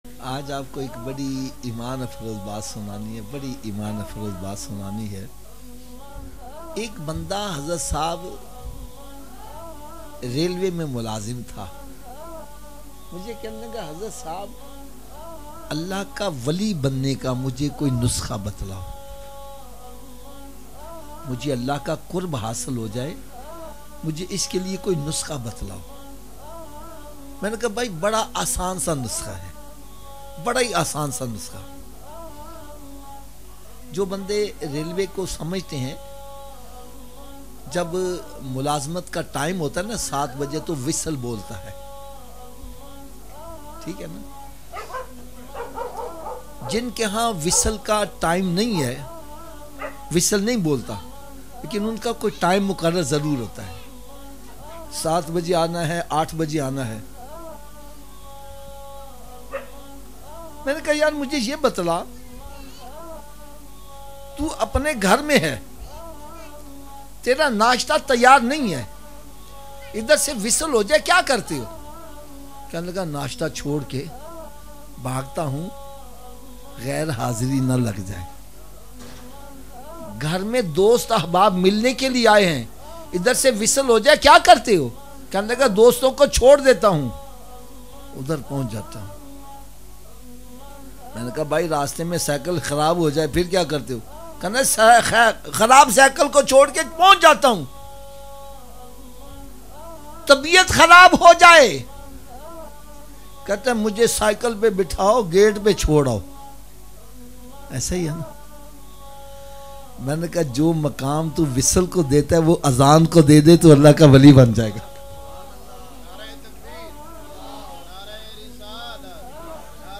Allah Ka Wali Banne Ka Tarika Islahi Bayan 2020 Download